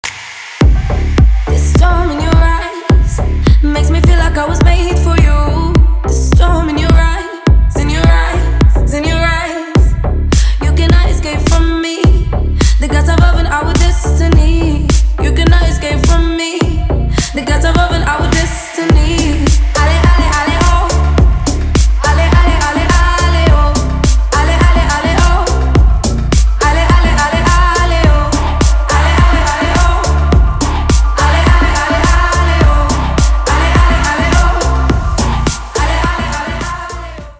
• Качество: 320, Stereo
deep house
Club House
басы
Трек в стиле deep house, club house.